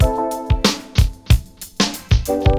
• 92 Bpm High Quality Breakbeat Sample A Key.wav
Free breakbeat sample - kick tuned to the A note. Loudest frequency: 1202Hz
92-bpm-high-quality-breakbeat-sample-a-key-eUK.wav